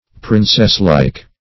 \Prin"cess*like`\
princesslike.mp3